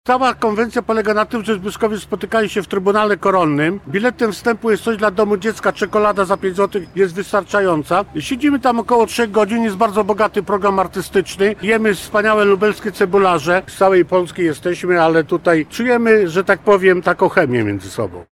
Więcej na temat zjazdu mówi pomysłodawca wydarzenia, Radny Rady Miasta Lublina Zbigniew Jurkowski